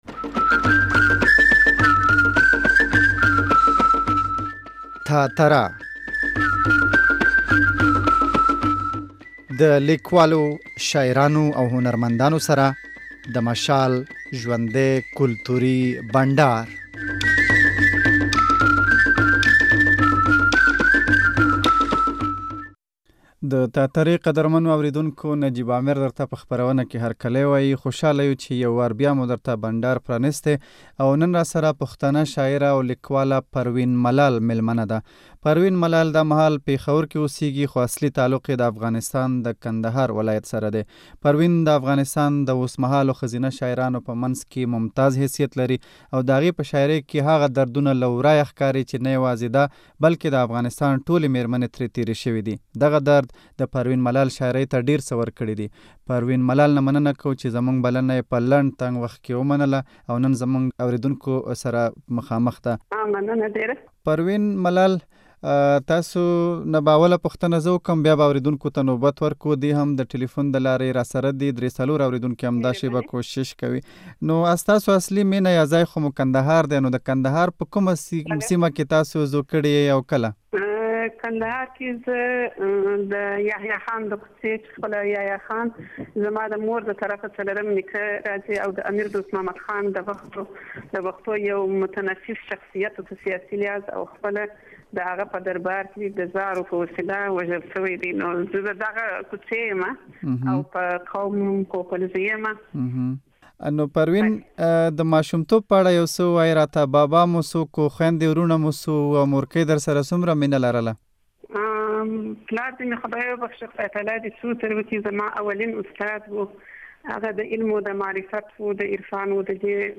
داخپرونه چې د ګل په ورځ نیغ په نیغه خپره شوې وه